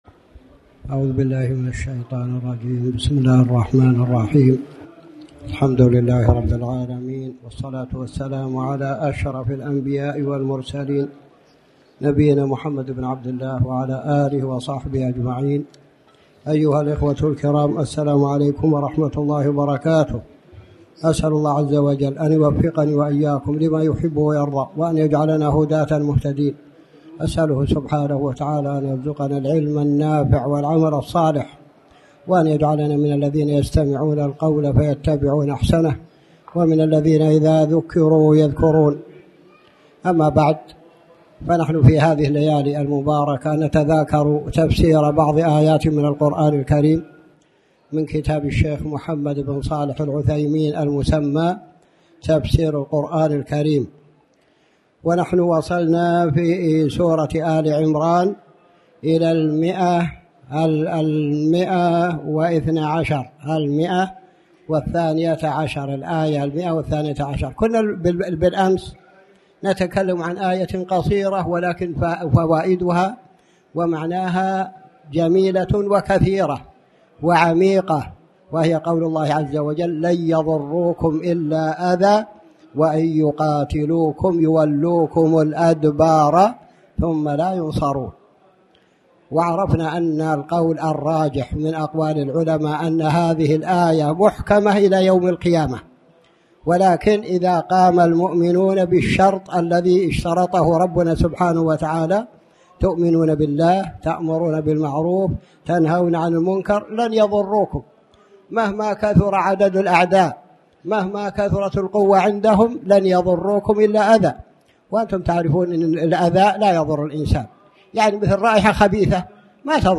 تاريخ النشر ٢٩ ربيع الأول ١٤٣٩ هـ المكان: المسجد الحرام الشيخ